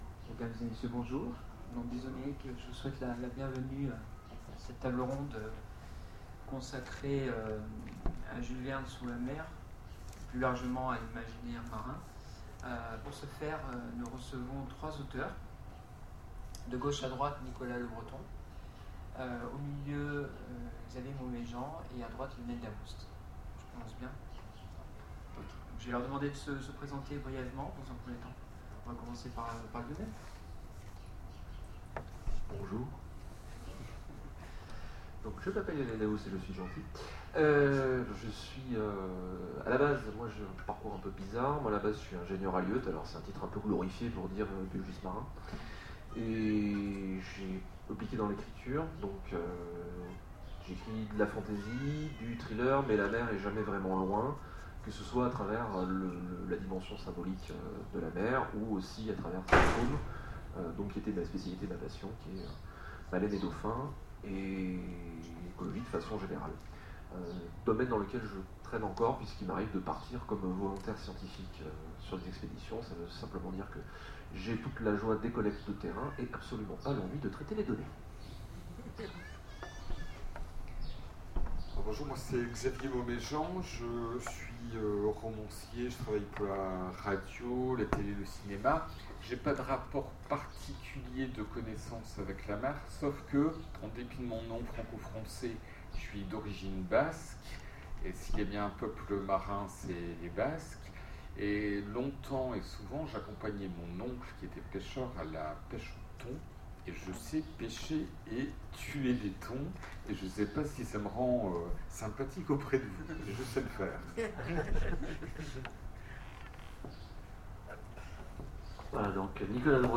Les Oniriques 2015 : Table ronde Verne sous la mer
Conférence